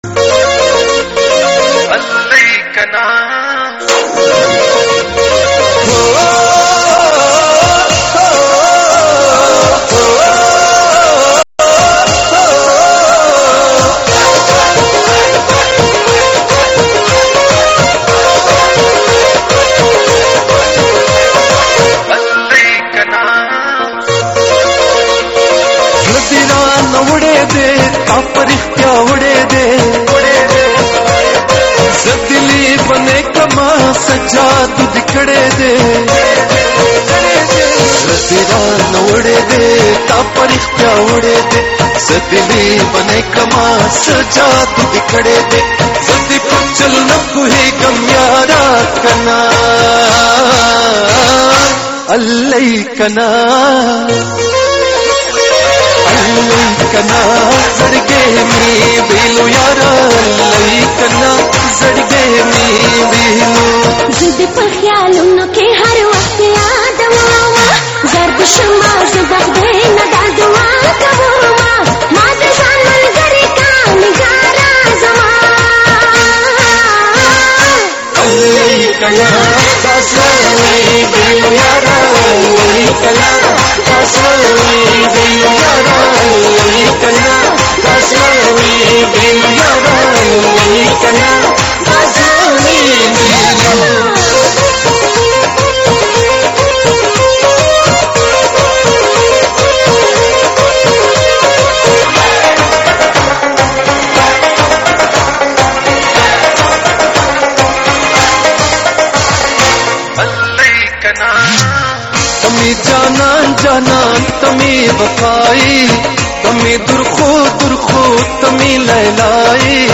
مسته سندره
په غبرګو اوازو يي سره وايي